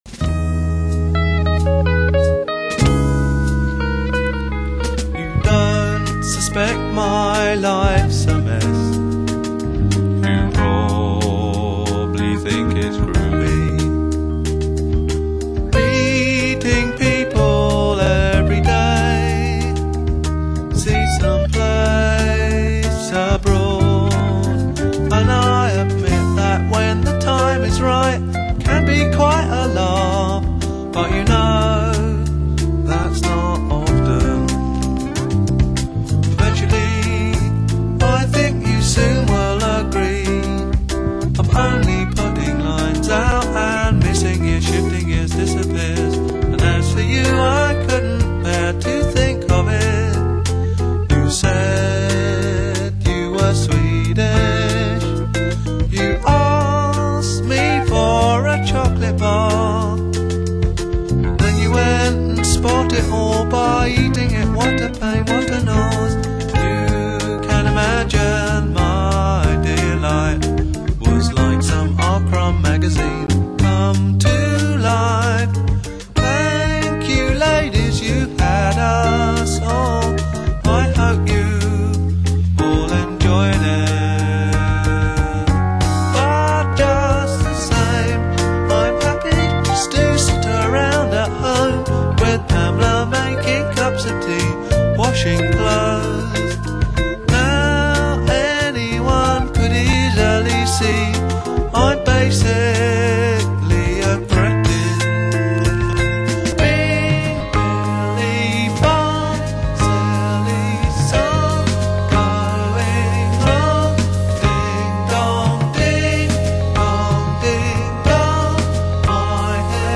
BBC Session
The Canterbury Sound loomed large.